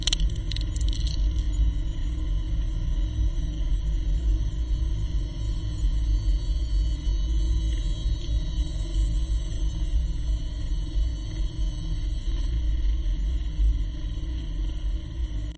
机器嗡嗡声2
描述：Xbox 360的机械/电气嗡嗡声和嗡嗡声，用ZOOM（不记得是哪个型号，因为我是为学校项目租的）在机器底部附近录制的。
标签： 风扇 360 XBOX 电子 氛围 机械 工业 机械 空气 嗡嗡声 嗡嗡声 环境 噪声 音响
声道立体声